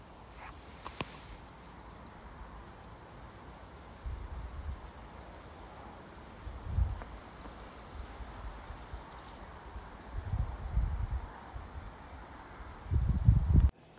Birds -> Waders ->
Common Sandpiper, Actitis hypoleucos